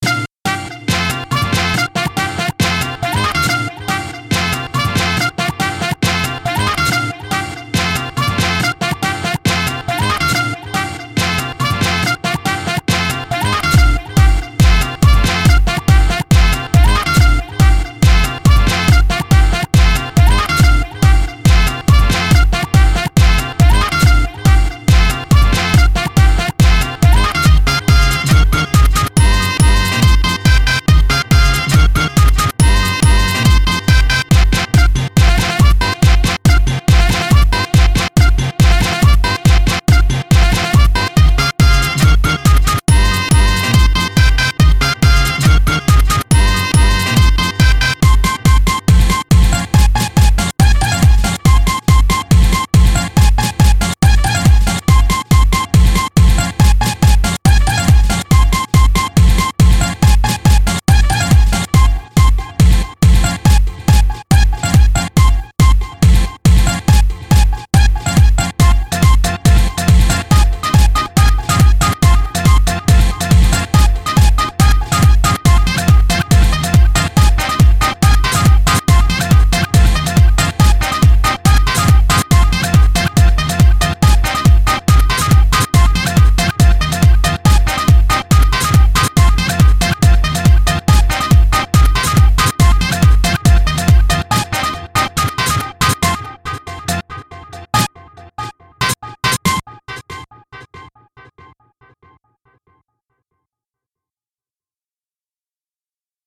Genre Electronica